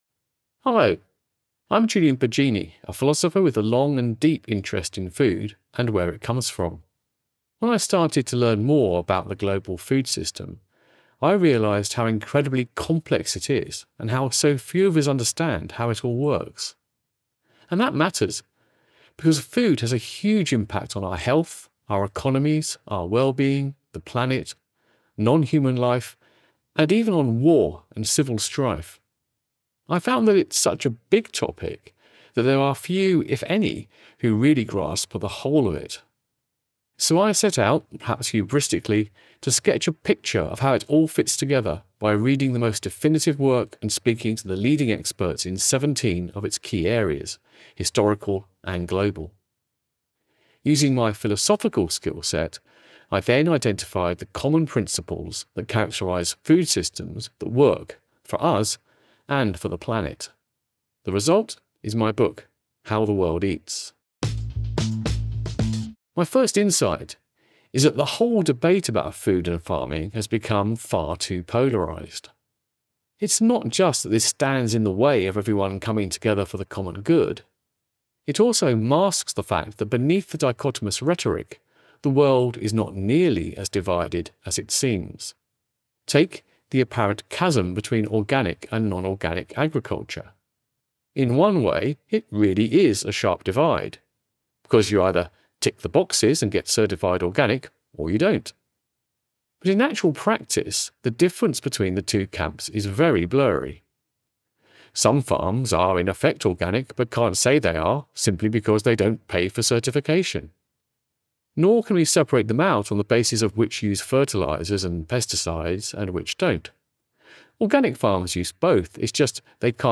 Listen to the audio version—read by Julian himself—in the Next Big Idea App.